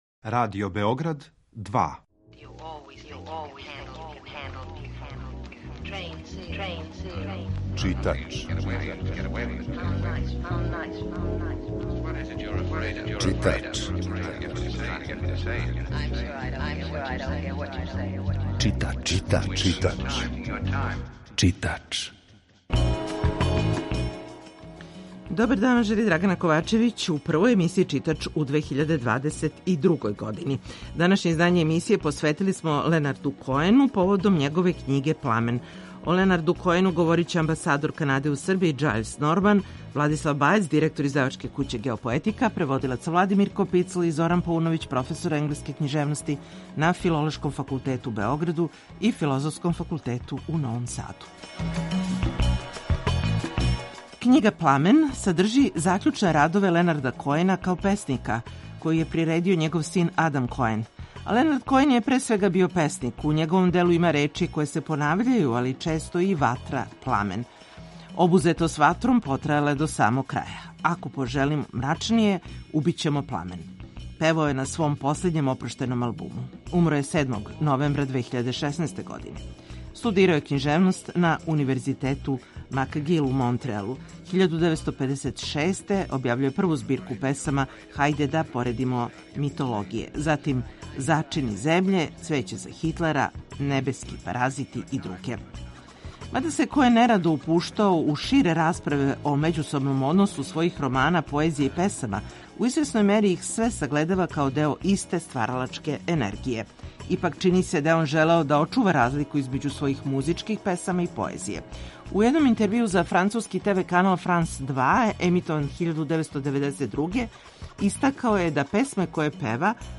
Емисија је колажног типа
За емисију Читач говоре: амбасадор Kанаде у Србији, Џајлс Норман